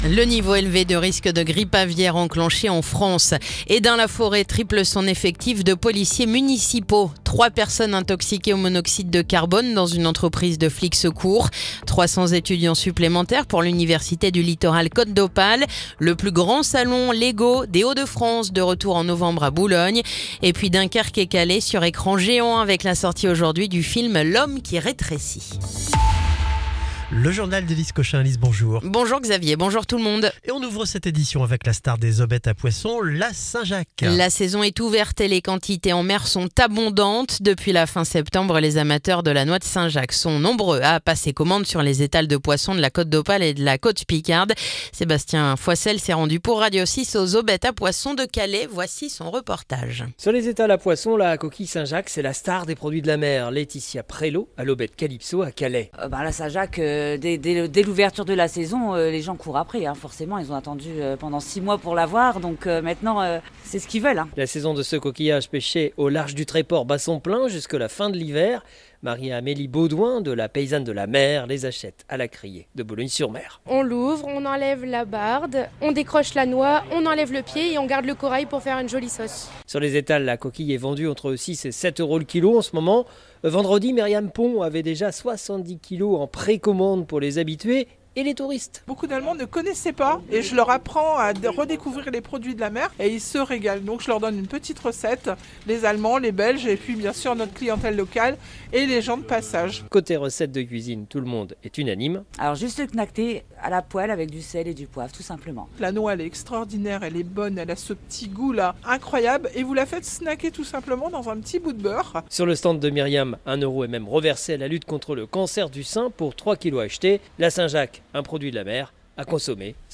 Le journal du mercredi 22 octobre